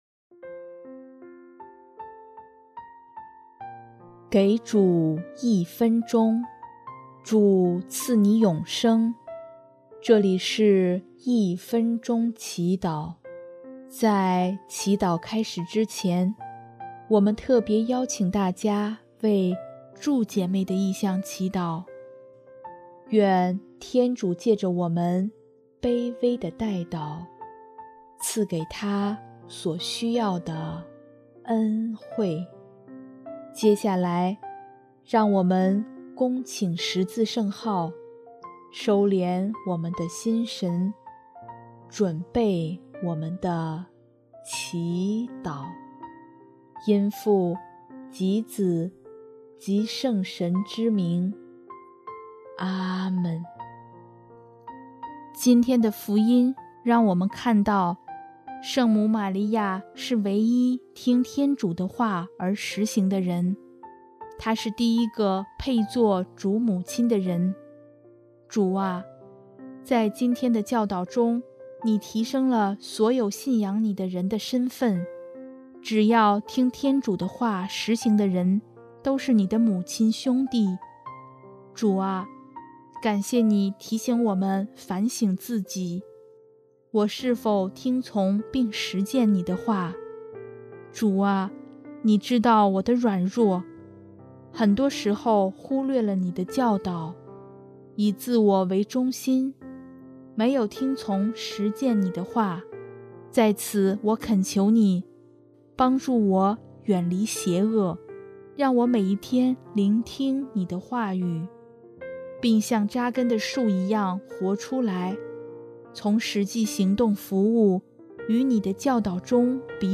【一分钟祈祷】|9月23日 听天主的话而实行的才是有福的